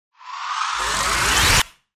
FX-1010-WIPE
FX-1010-WIPE.mp3